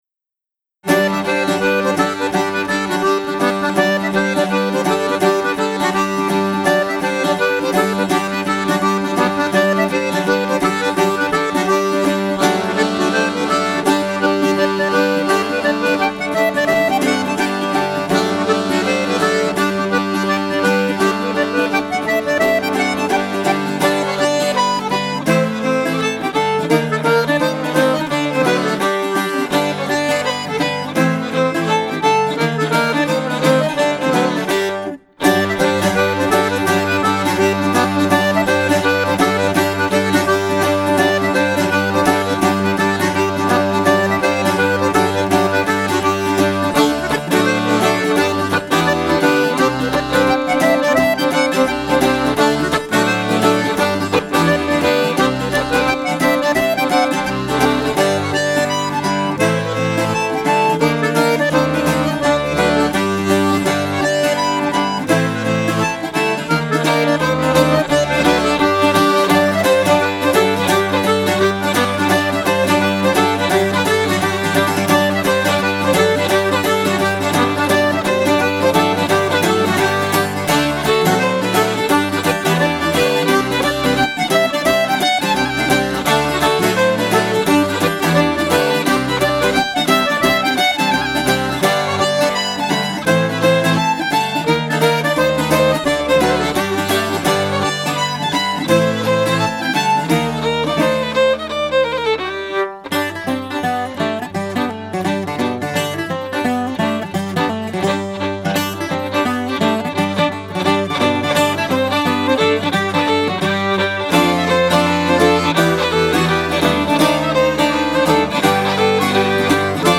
bouzouki
violon
clarinette
trad wallon